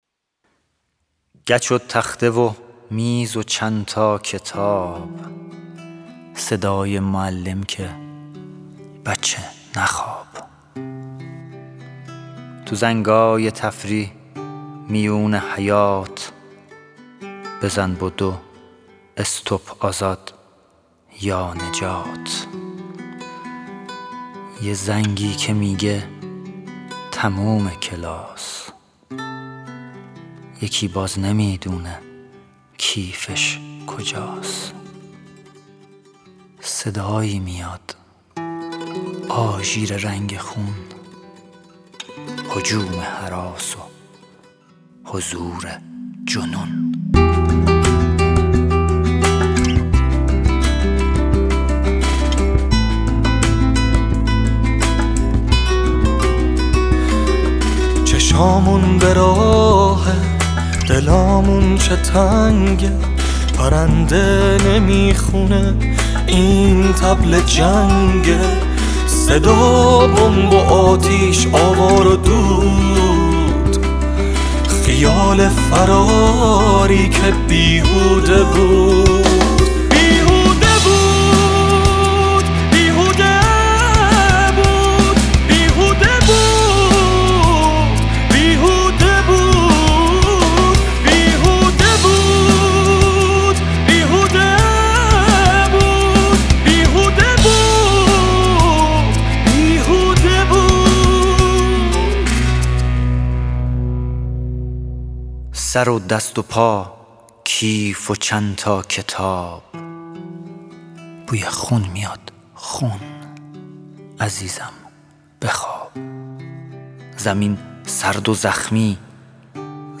ترانه